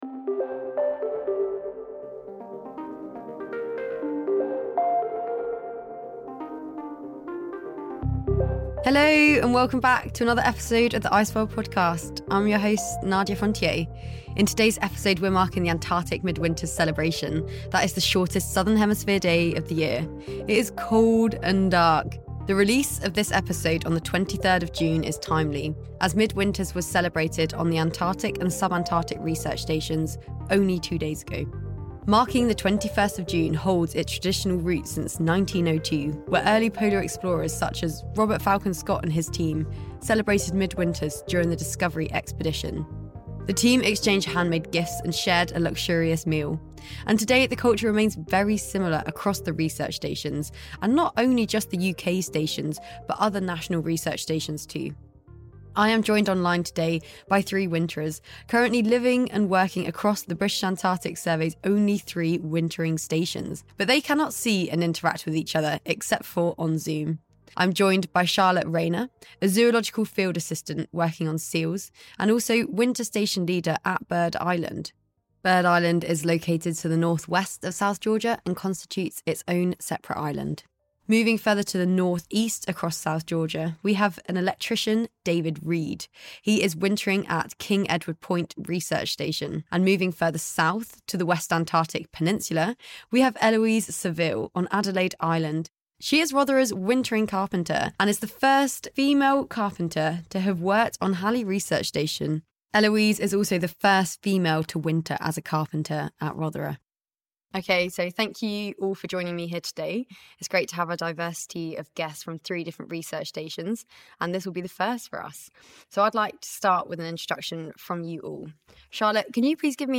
cross-Antarctic conference call